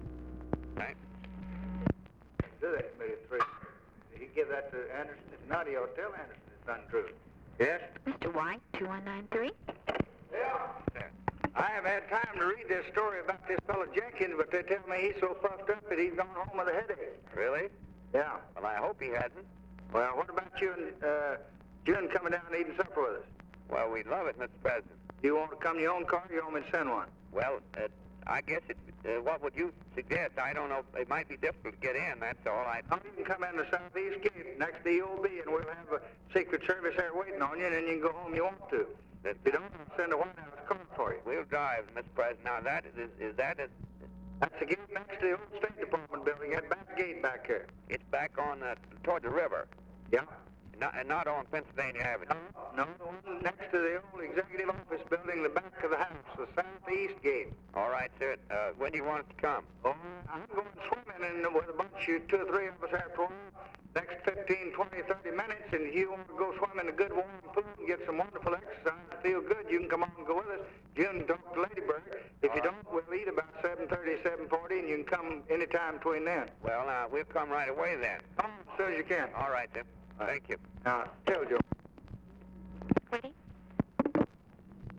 Conversation with WILLIAM S. WHITE, December 9, 1963
Secret White House Tapes